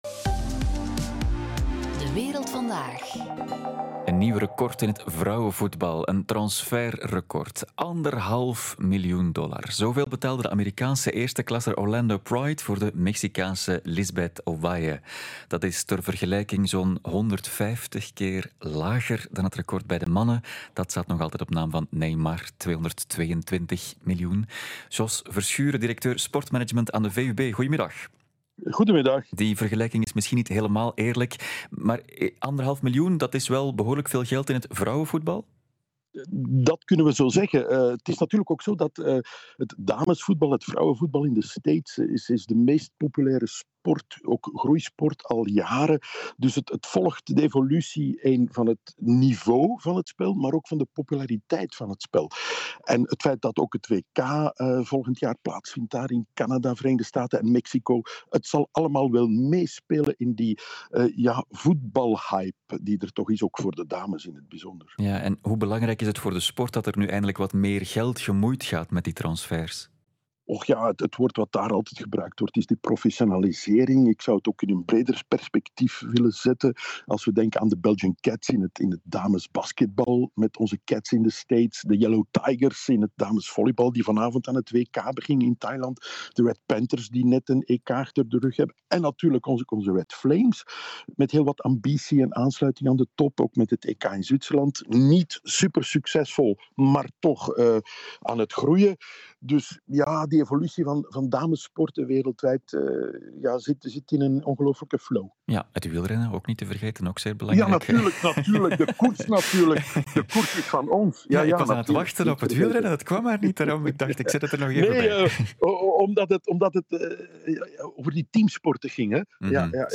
Interview Radio 1